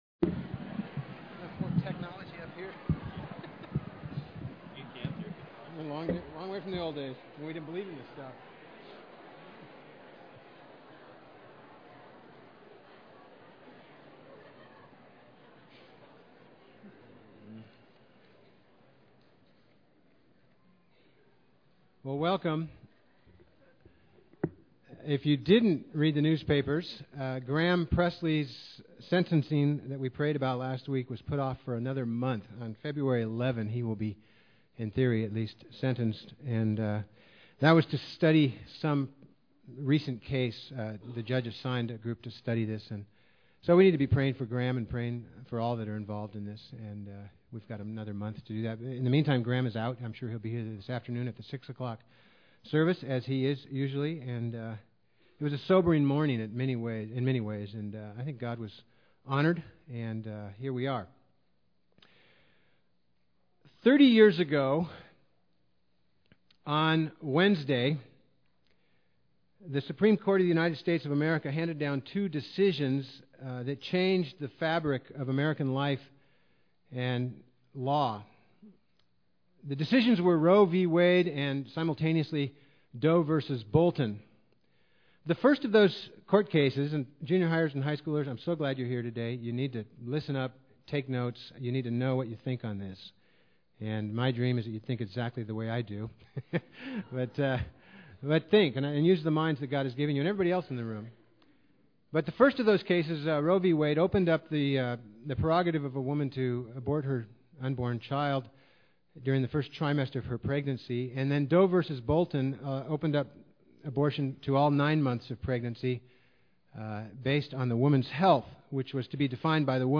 Psalm 139 | Santa Barbara Community Church